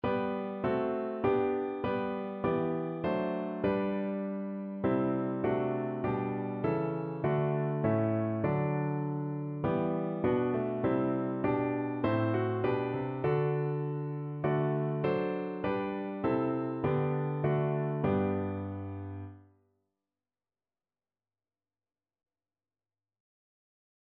No parts available for this pieces as it is for solo piano.
4/4 (View more 4/4 Music)
Piano  (View more Intermediate Piano Music)
Christian (View more Christian Piano Music)